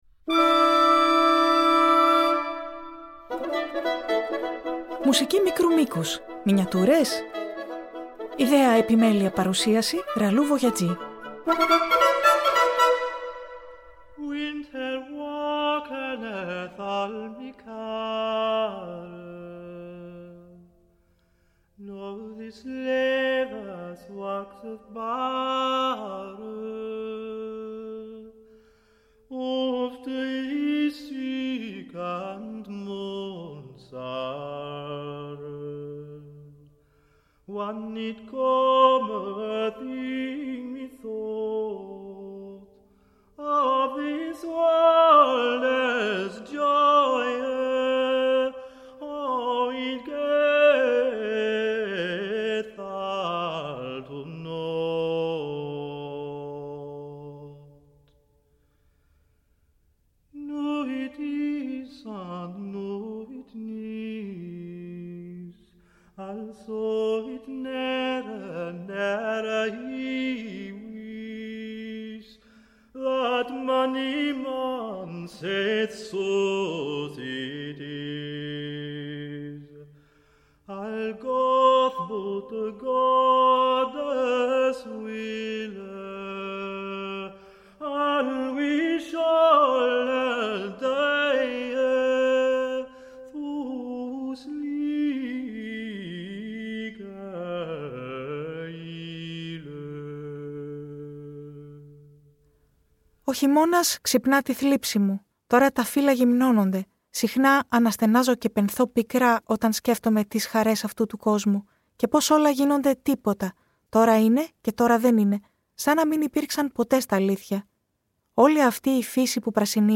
ΜΙΚΡΟΣ ΧΕΙΜΩΝΑΣ Μια ακόμα περιήγηση με μουσική μικρής διάρκειας: από τον Joseph Haydn στον John Cage , από την επαρχία της Αγγλίας , στη σκοτεινή βαρυχειμωνιά της Βιέννης , στην κρυστάλλινη παγωνιά της Ρωσίας .